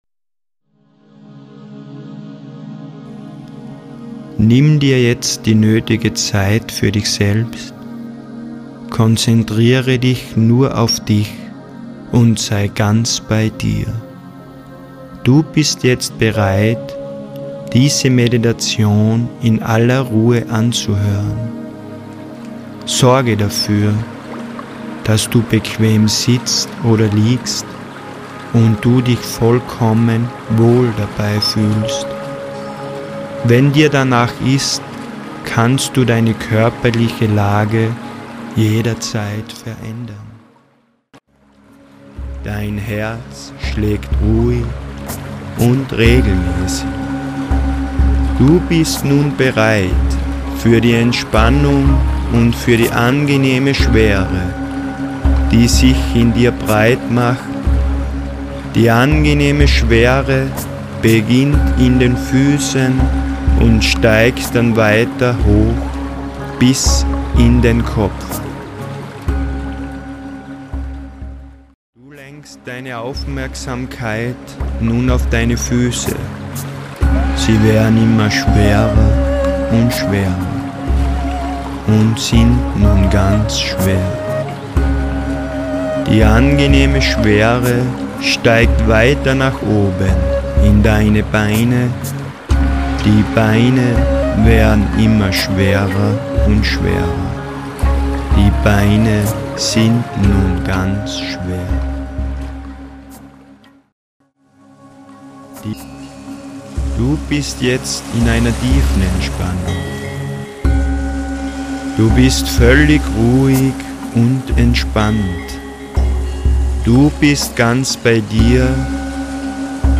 Die neuartige Meditations-CD
mit Planetentönen, Klangschalen und Walgesängen
1. Meditation mit Tiefenentspannung